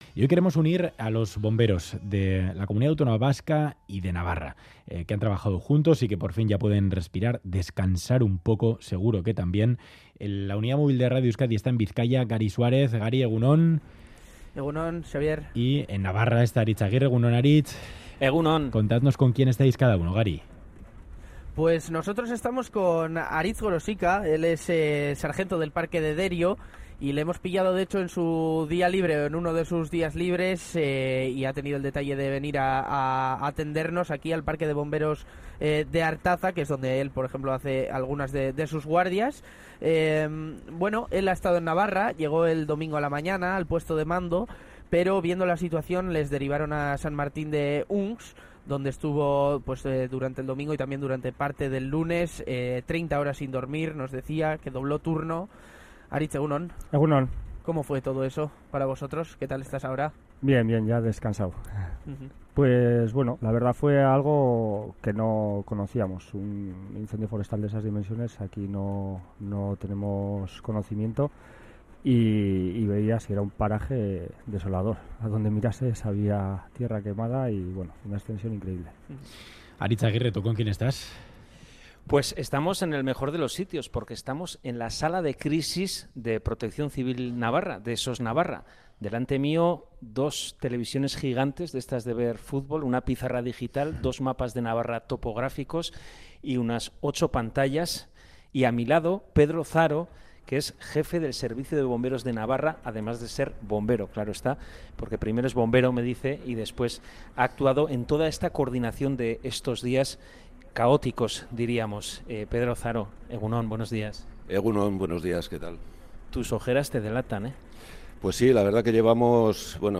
Audio: Incendios en Navarra, declaraciones de los bomberos